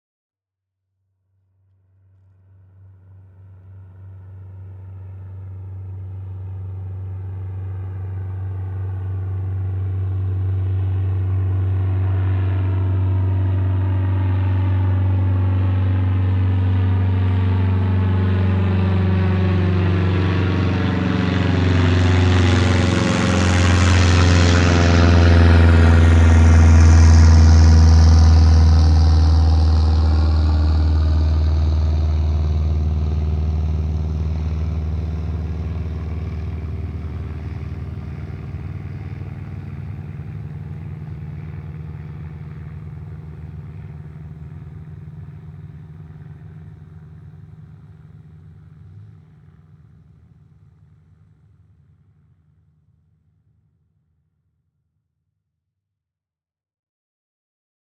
A plane
small-plane.wav